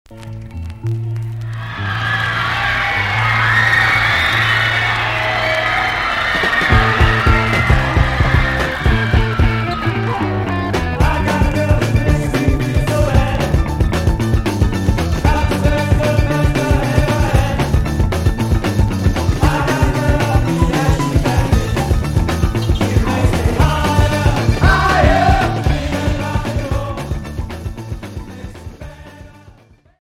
Rock Premier 45t retour à l'accueil